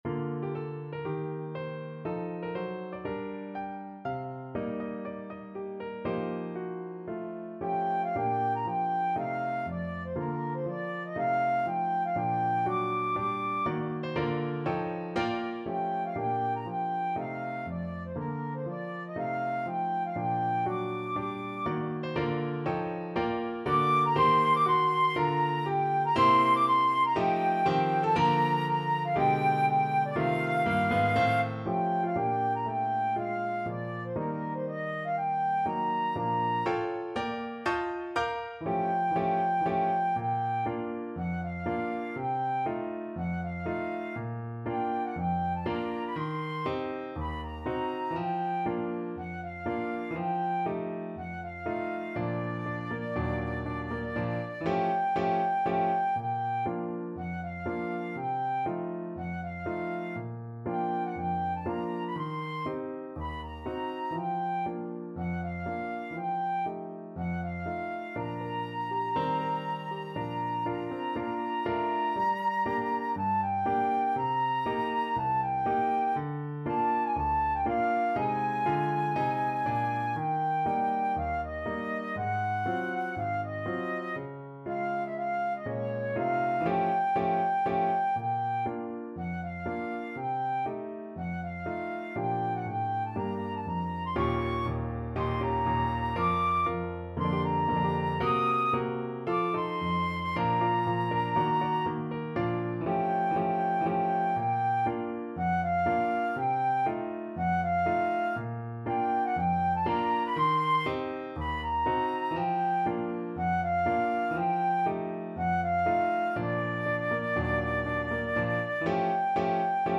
4/4 (View more 4/4 Music)
~ = 120 Moderato